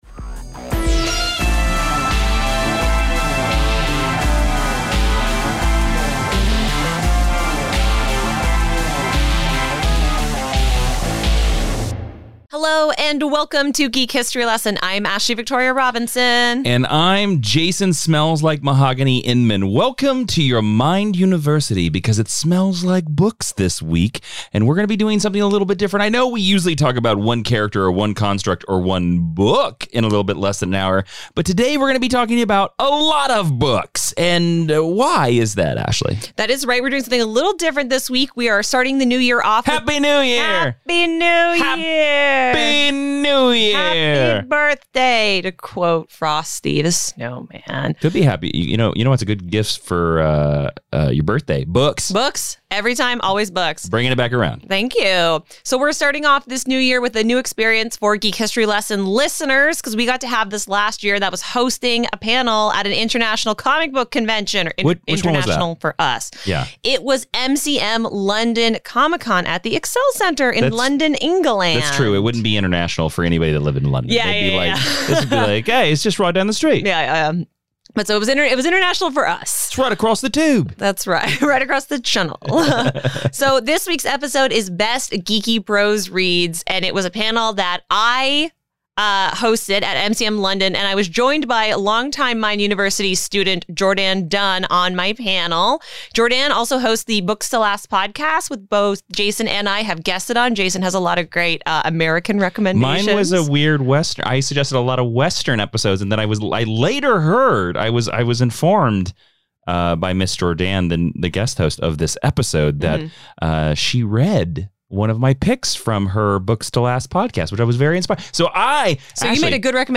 Recorded LIVE at MCM London 2021!